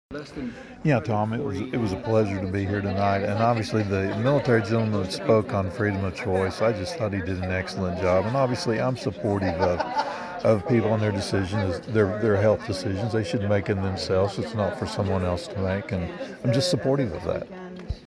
Commissioner Dunlap said,